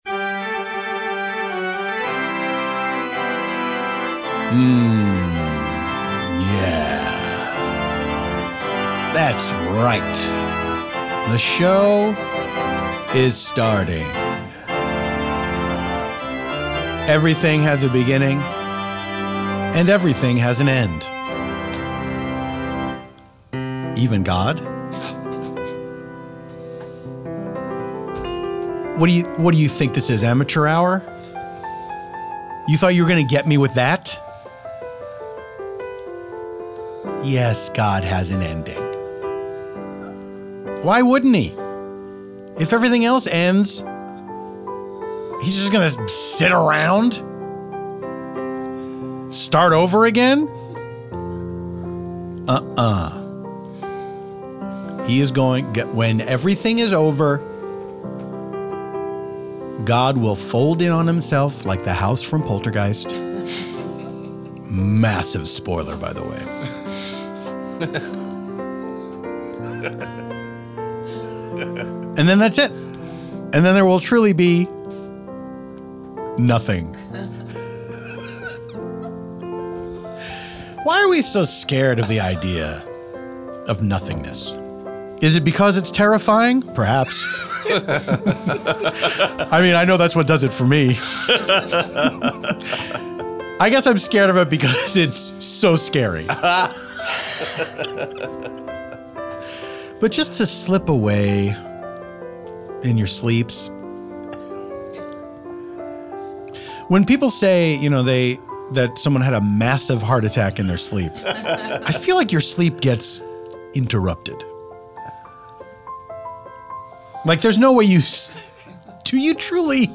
This time out, Paul's special guest is actor/comedian Scott Thompson of The Kids in the Hall! Scott chats about what song he puts on when a hookup comes over, becoming content with himself, and staging an countrywide intervention for the US.
scores it all on piano!